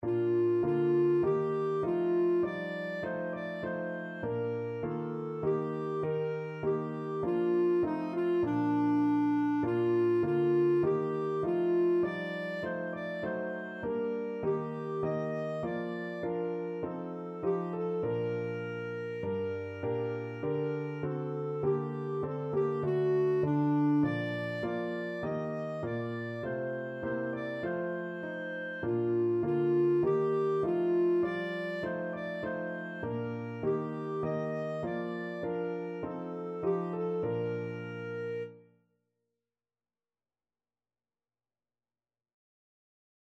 Clarinet
4/4 (View more 4/4 Music)
Bb major (Sounding Pitch) C major (Clarinet in Bb) (View more Bb major Music for Clarinet )
Classical (View more Classical Clarinet Music)